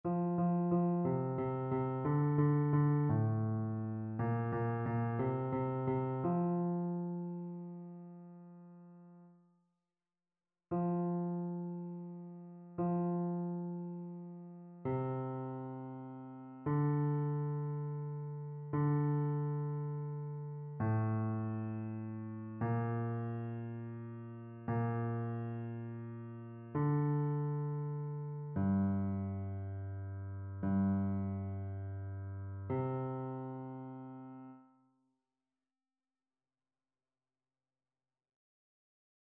Chœur
Basse
annee-a-temps-ordinaire-28e-dimanche-psaume-22-basse.mp3